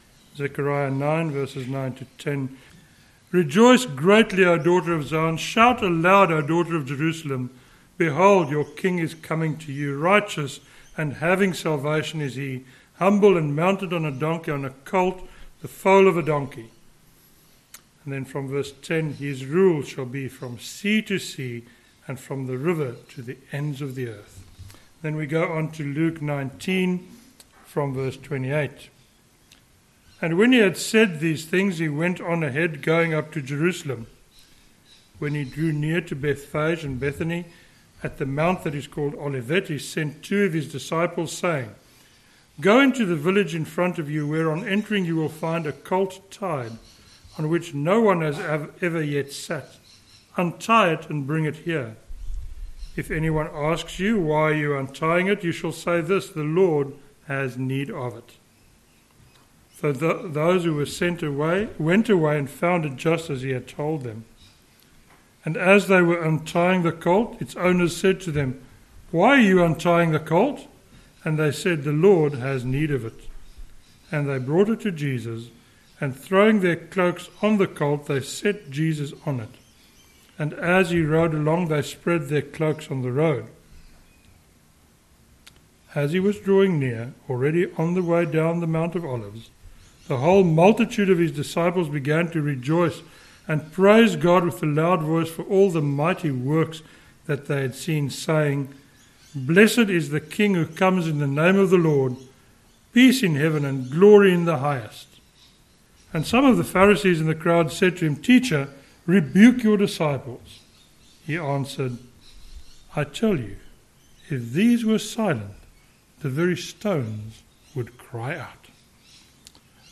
a sermon on Luke 19:28-40